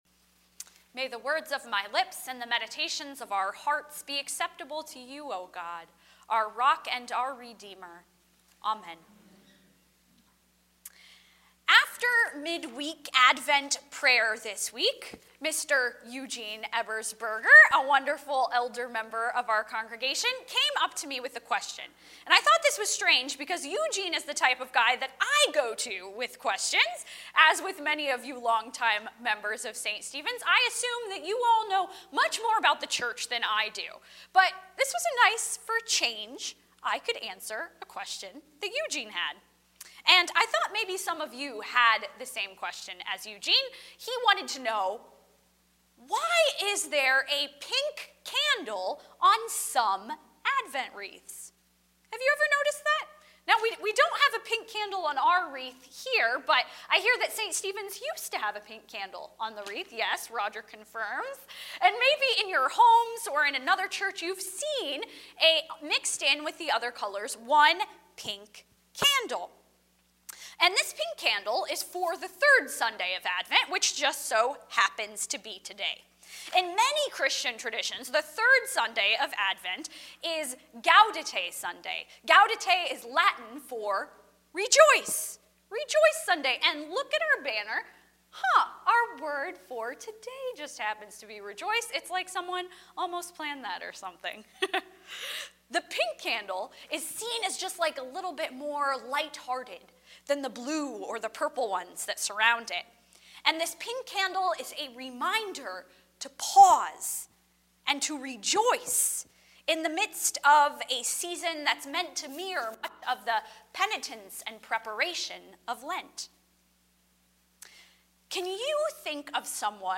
Service Type: Sunday Morning 10:30